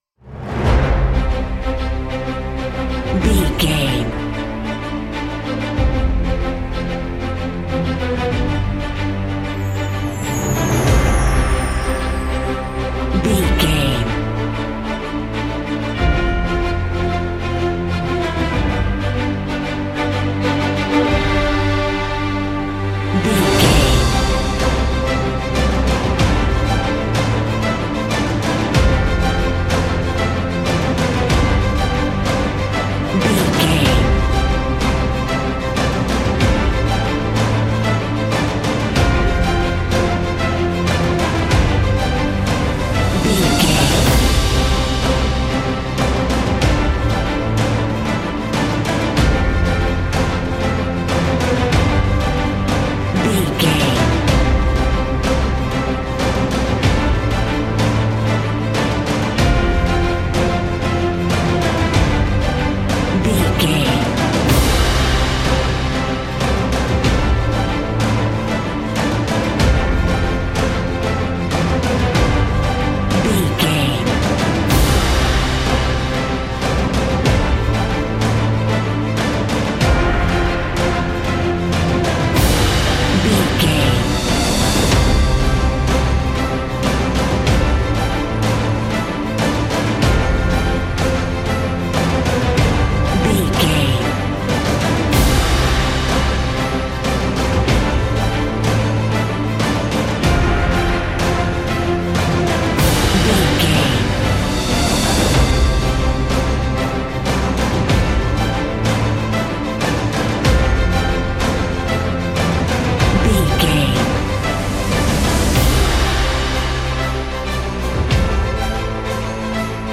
Uplifting
Ionian/Major
WHAT’S THE TEMPO OF THE CLIP?
brass
percussion
strings